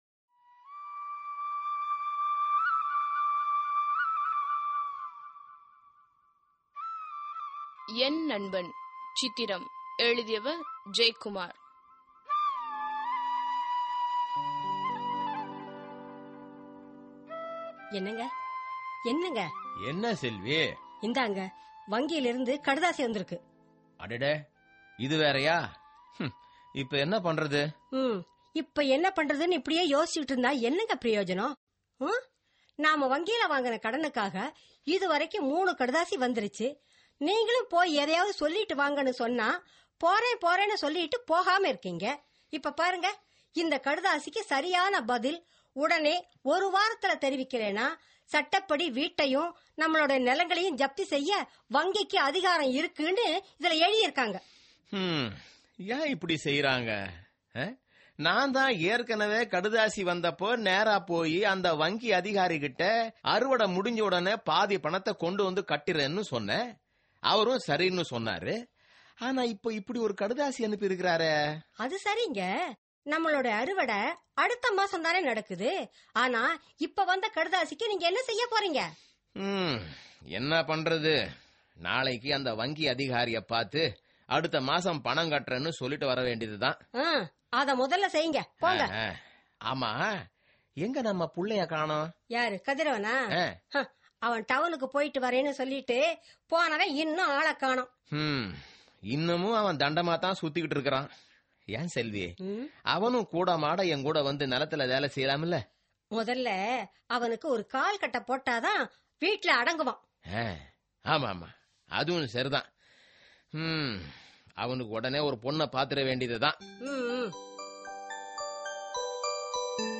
Social Drama